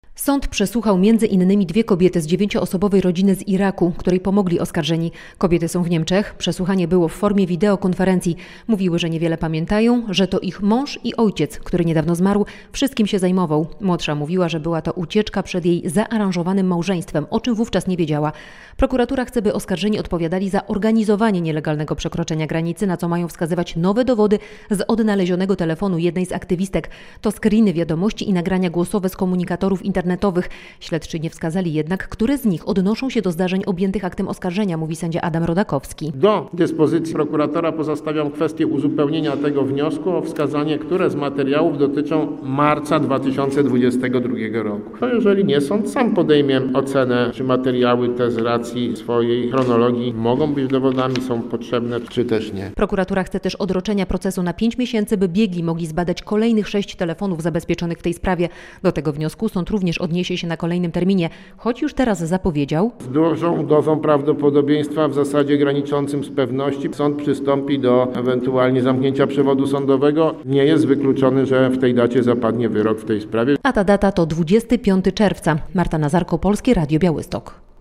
Koniec procesu aktywistów na granicy z Białorusią coraz bliżej - relacja